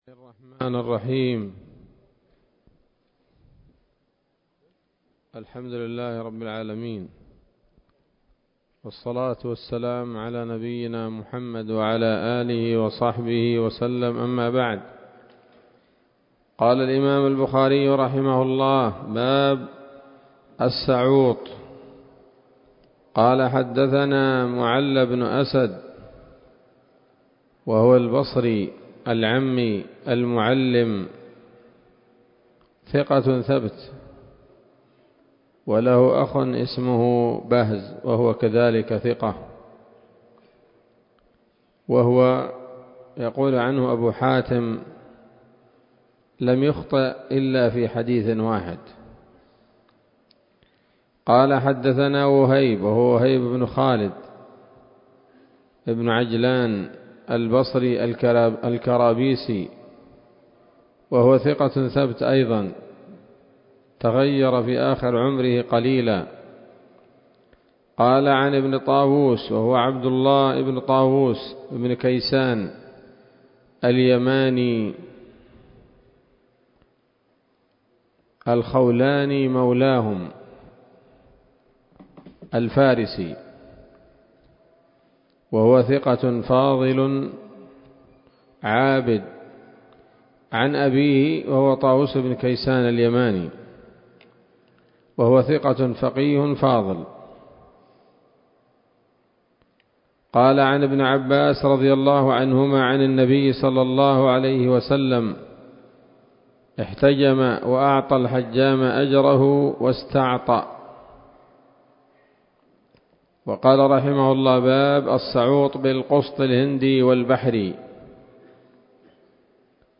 الدرس الثامن من كتاب الطب من صحيح الإمام البخاري